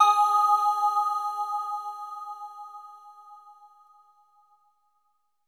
LEAD G#4.wav